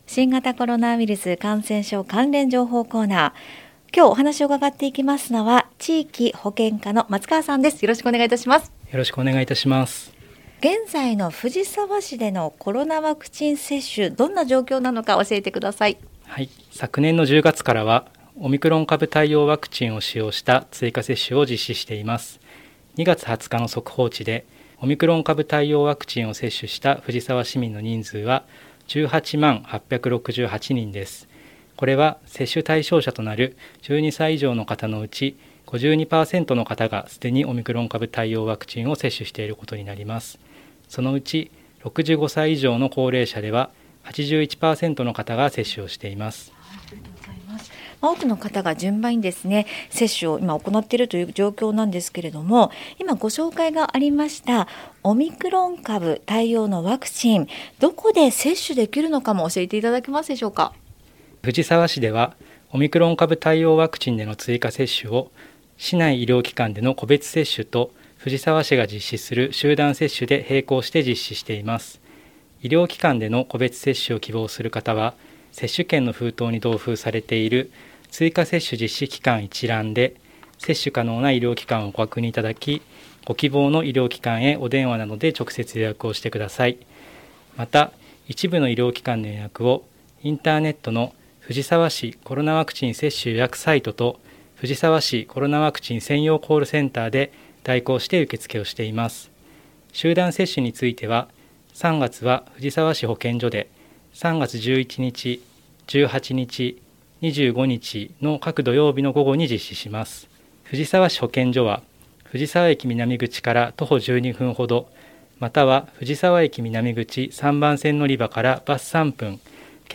令和4年度に市の広報番組ハミングふじさわで放送された「新型コロナウイルス関連情報」のアーカイブを音声にてご紹介いたします。